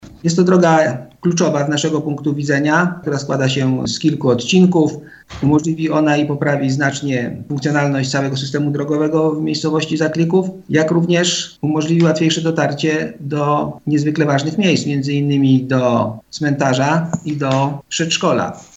Mówi burmistrz Zaklikowa Dariusz Toczyski: